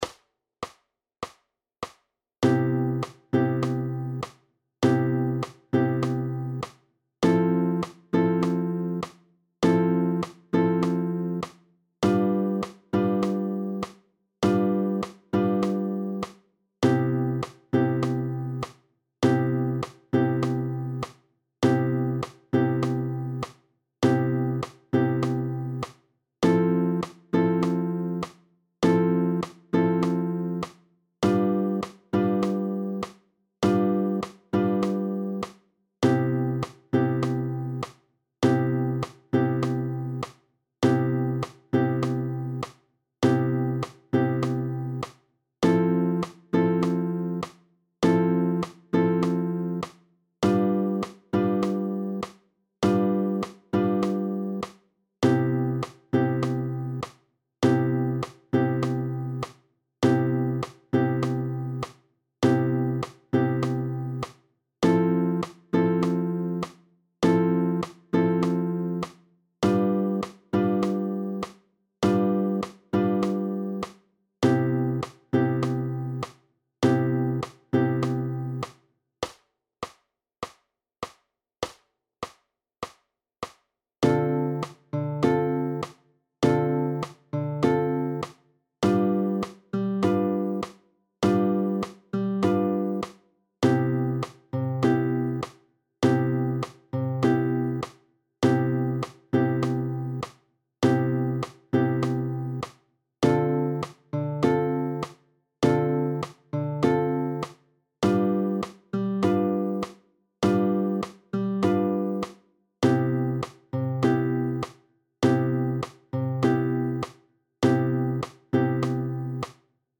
1.2.1.) Akkordverbindungen
• Akkordfolgen von Stufenakkorde in C-Dur (GT C auf E-Saite): PDF
I.2.-Akkordverb.-4sti.-Jazzch.-in-C-Dur-Grundton-6.-Saite.mp3